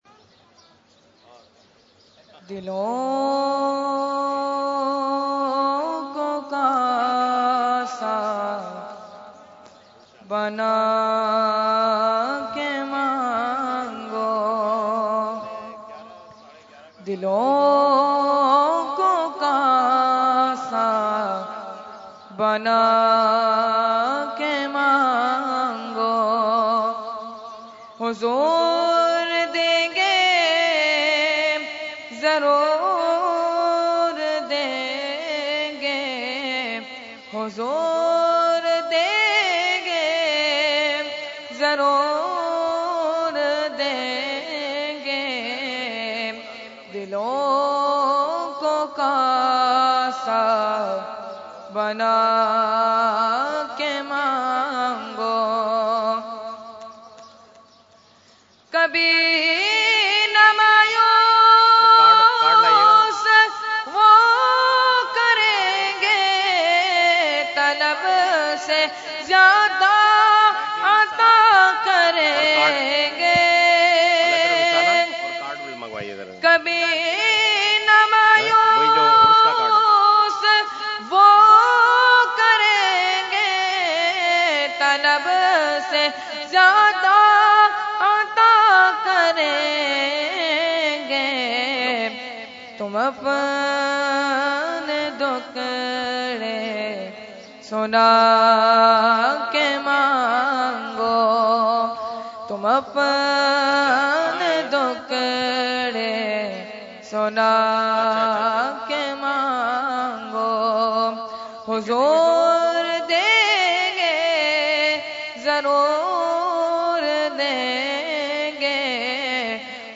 Category : Naat | Language : UrduEvent : Urs Qutbe Rabbani 2016